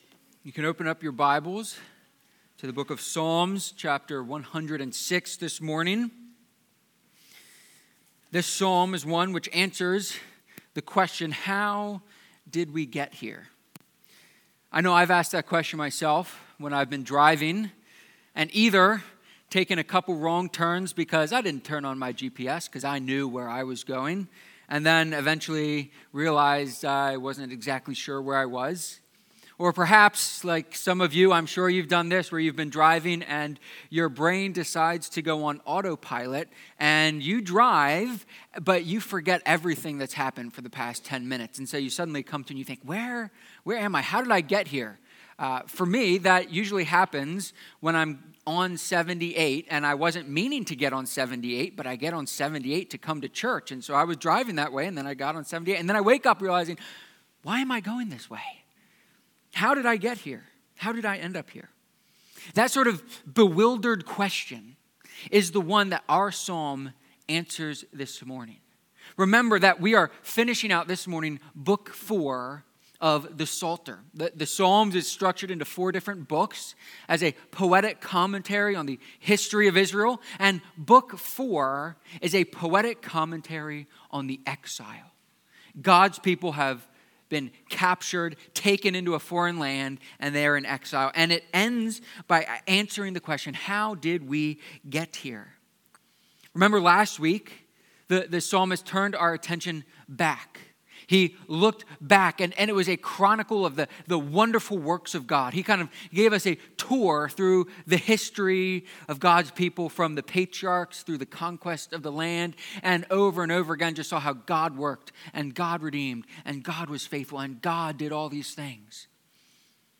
Psalm-106-sermon.mp3